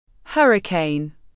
Hurricane /ˈhʌr.ɪ.kən/